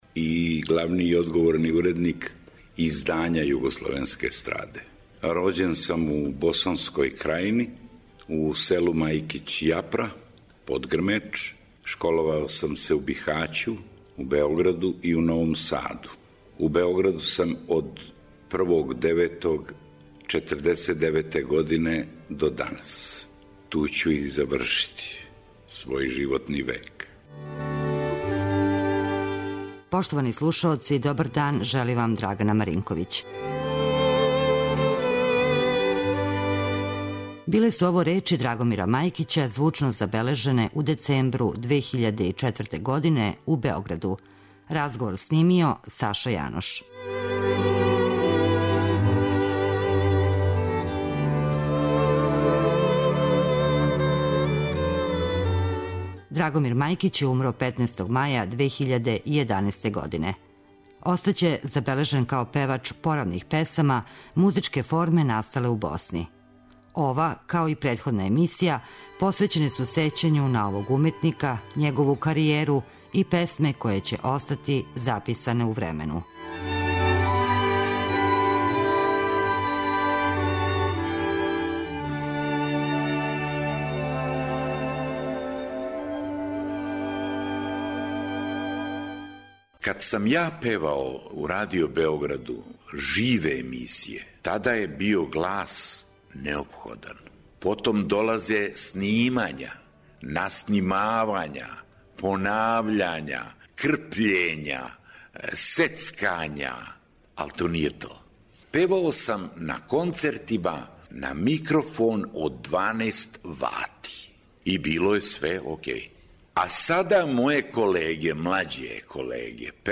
Говорићемо о његовој музичкој каријери и слушати записе који се налазе у тонском архиву Радио Београда.